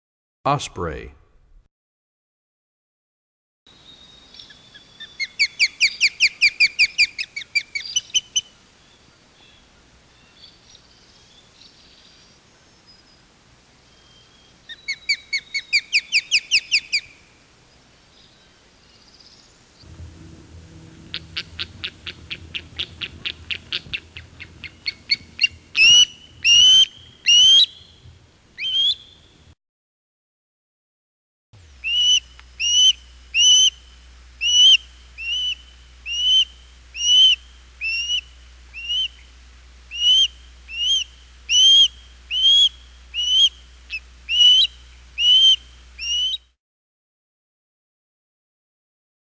64 Osprey.mp3